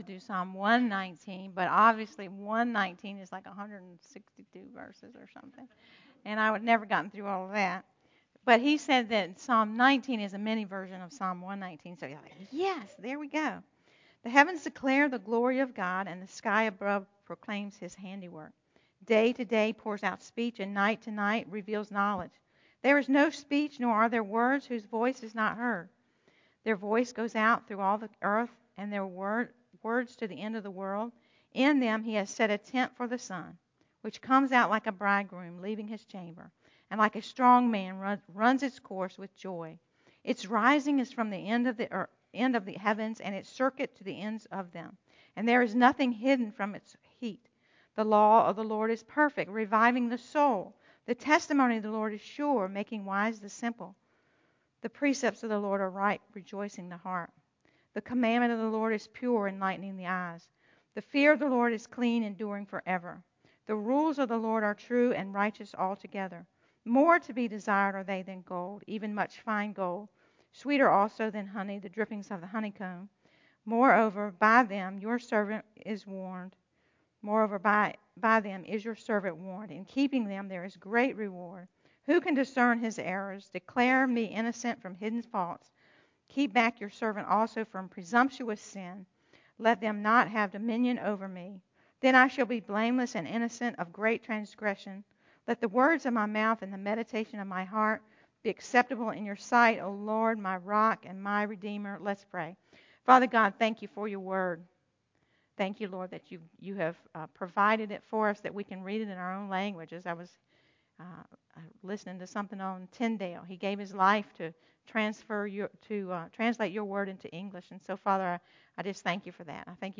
Ladies Bible Study